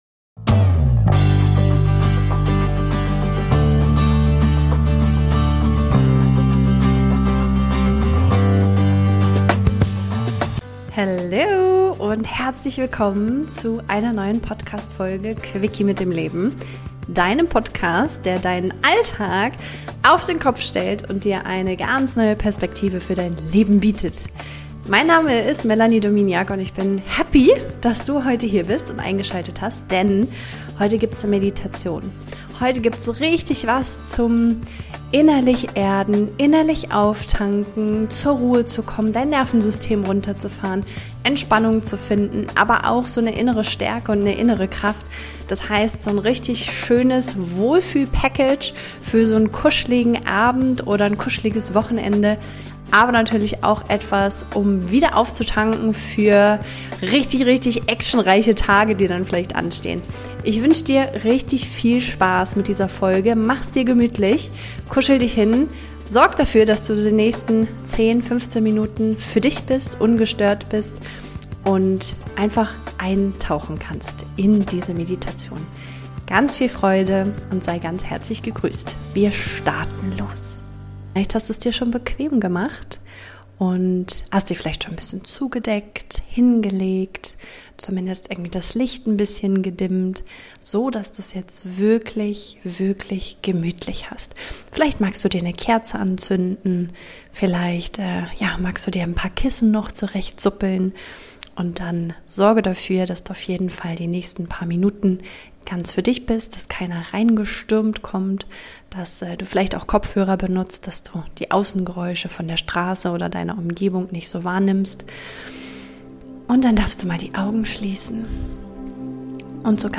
Lade dein Energiefeld auf - eine wohltuende Meditation ~ Quickie mit dem Leben Podcast